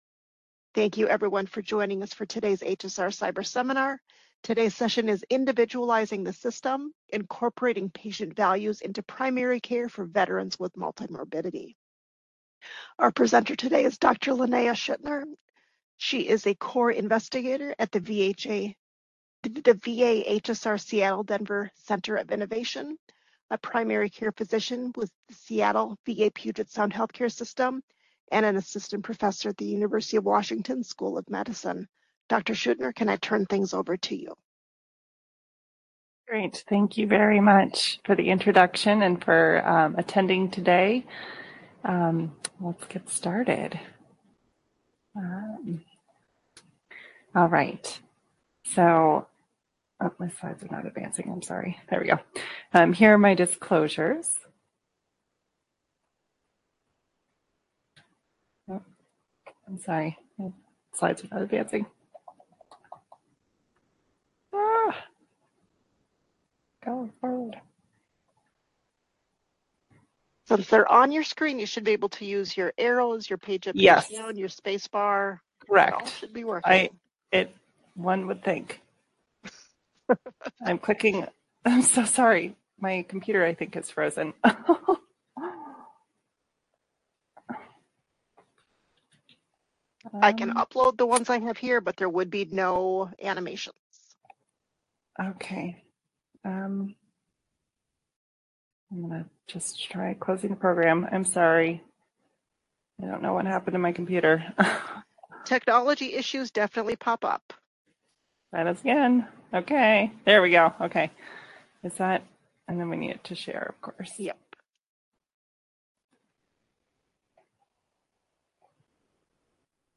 Description: This Cyberseminar presents the preliminary results of a VA Health Services Research Career Development Award (CDA) studying how patient values (what is meaningful in life and health) can be used in routine primary care delivery for Veterans with multimorbidity.